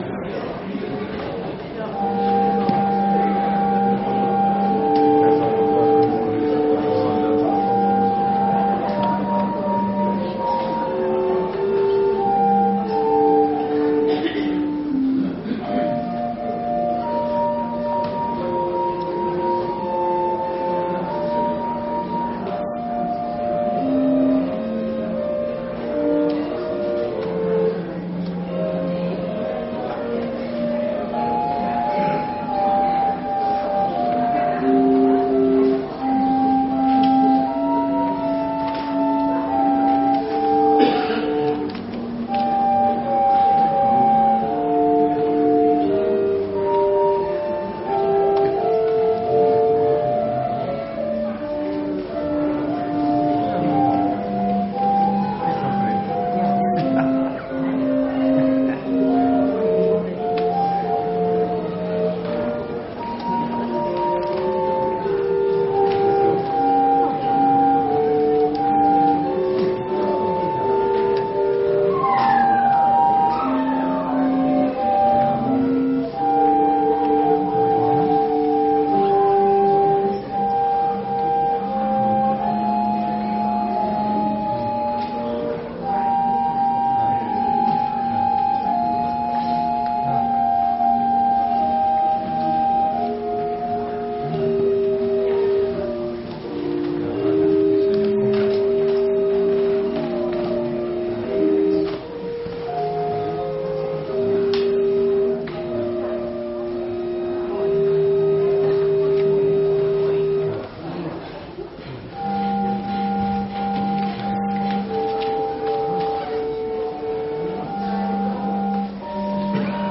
Gottesdienst Sonntag 09.02.2025 | Evangelisch-altreformierte Kirchengemeinde Laar
Wir laden ein, folgende Lieder aus dem Evangelischen Gesangbuch mitzusingen: Lied 177, 2, Lied 683, 1 – 5, Lied 391, 1 – 4, Lied 136, 1 – 4, Lied 644, 1 – 4, Psalm 37, 1 – 4